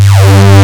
And here’s the result: 64 Wavetables of a sine wave, gradually undergoing sample rate reduction until it becomes a square wave.